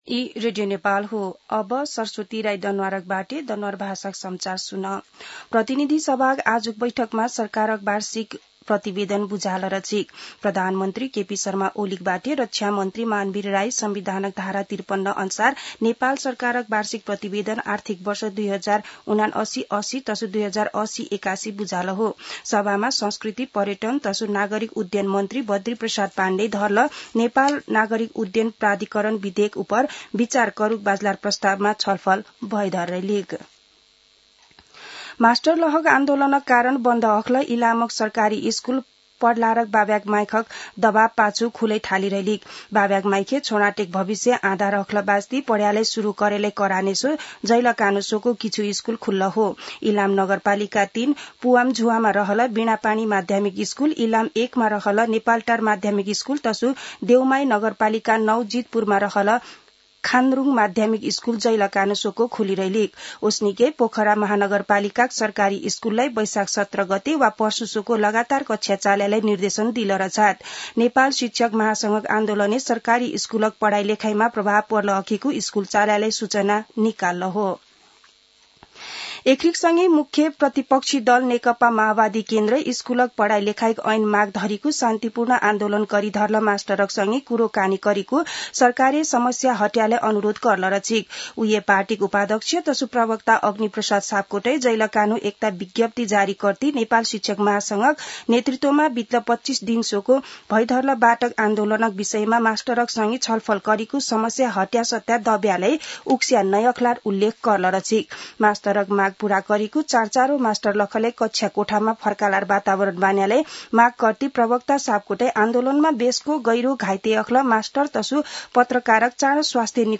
दनुवार भाषामा समाचार : १५ वैशाख , २०८२